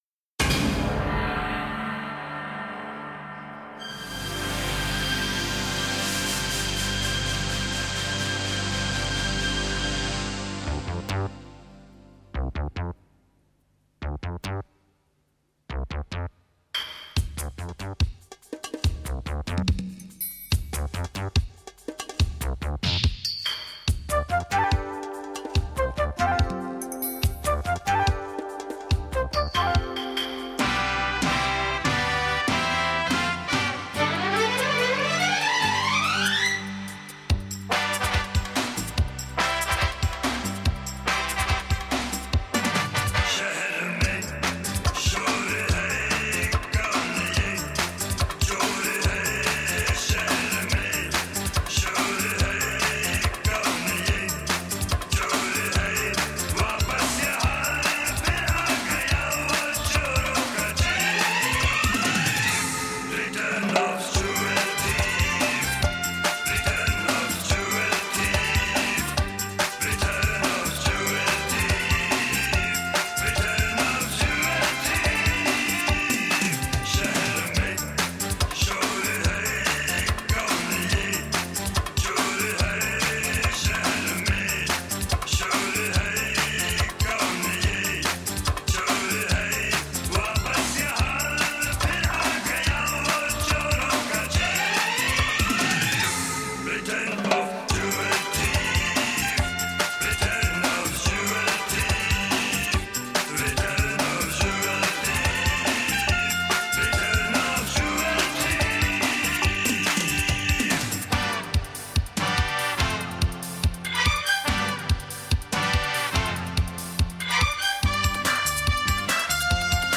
Hindi & Bollywood